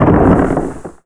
DEMOLISH_Short_08_mono.wav